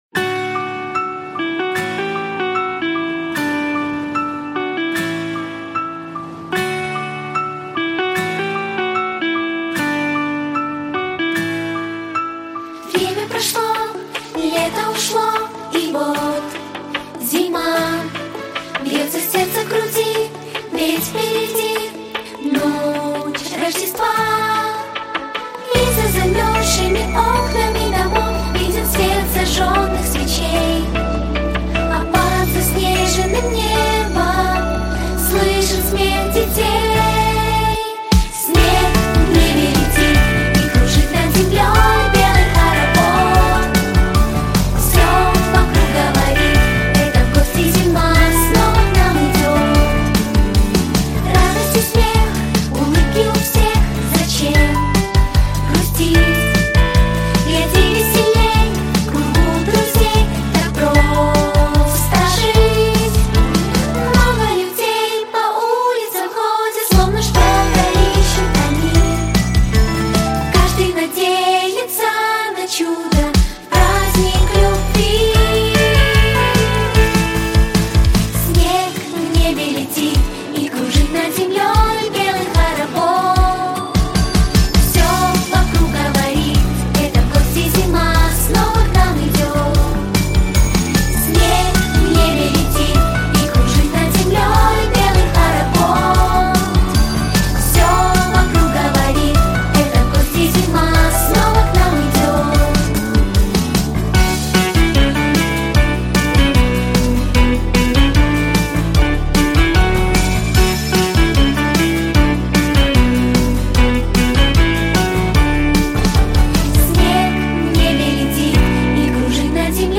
• Категория: Детские песни
🎶 Детские песни / Песни на праздник / Христианские Песни ⛪